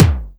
TOM74.wav